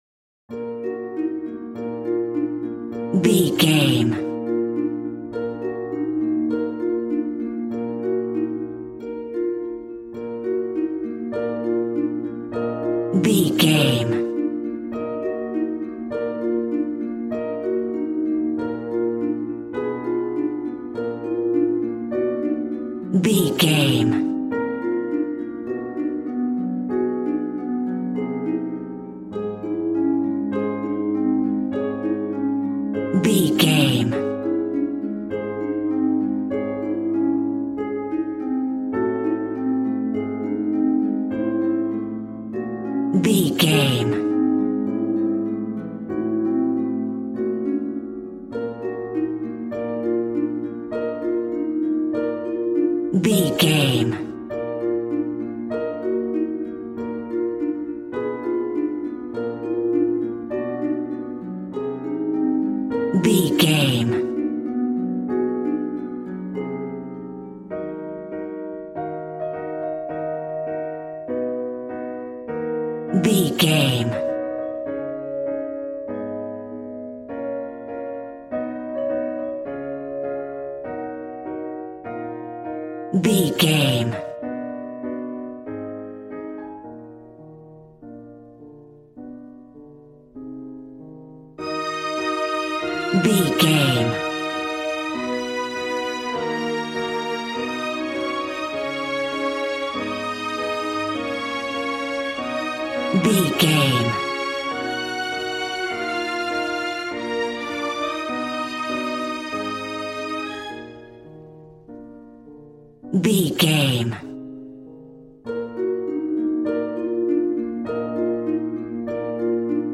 Aeolian/Minor
E♭
regal
strings
violin